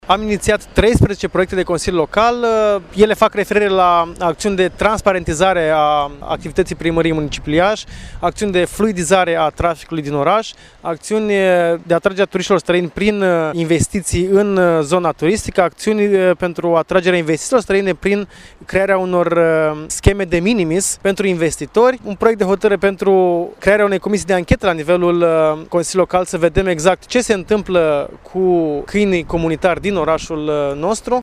Răzvan Timofciuc a explicat care sunt proiectele depuse de liberali: